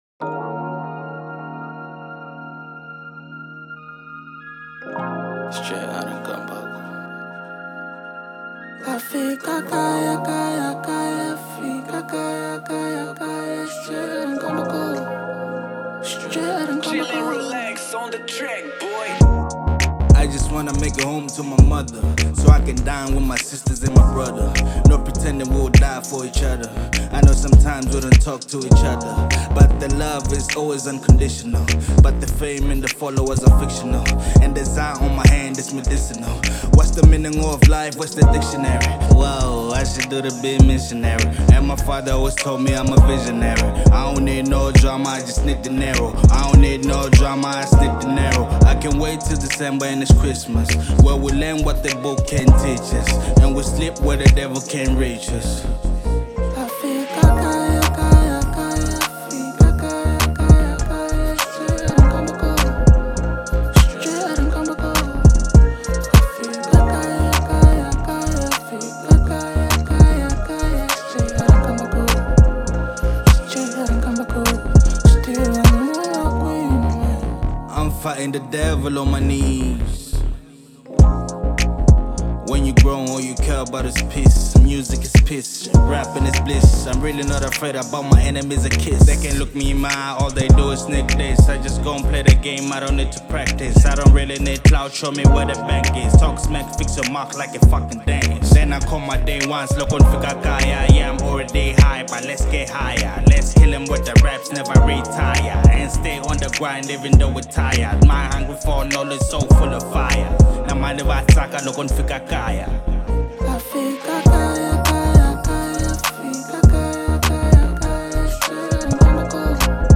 02:41 Genre : Hip Hop Size